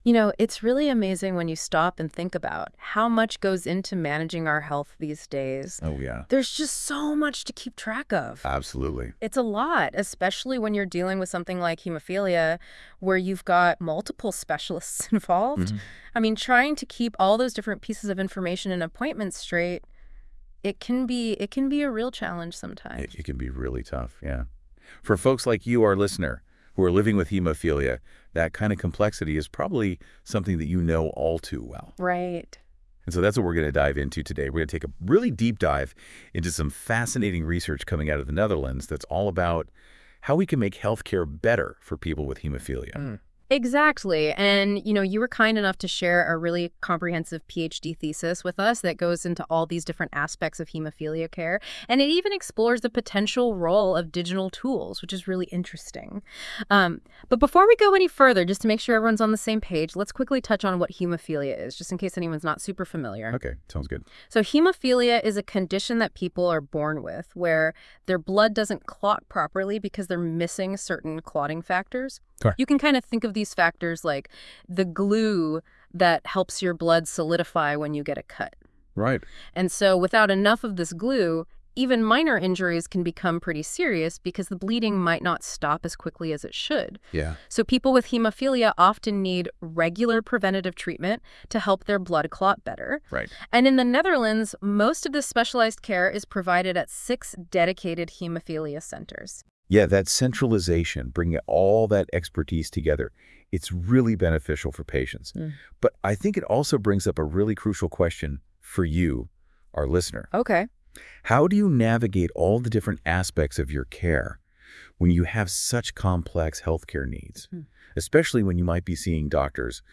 With AI a podcast is generated automatically from the thesis.